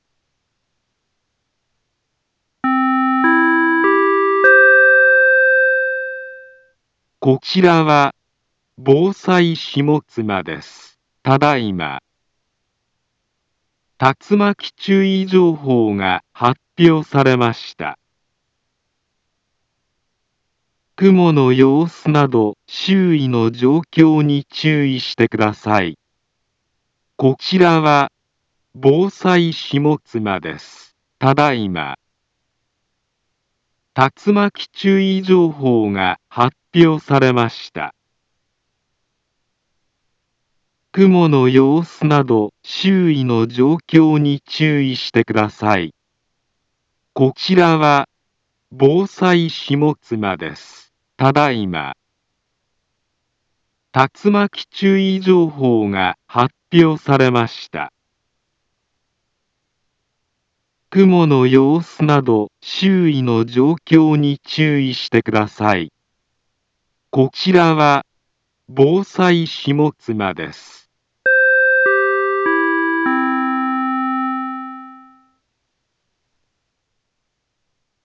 Back Home Ｊアラート情報 音声放送 再生 災害情報 カテゴリ：J-ALERT 登録日時：2023-09-08 16:04:59 インフォメーション：茨城県北部、南部は、竜巻などの激しい突風が発生しやすい気象状況になっています。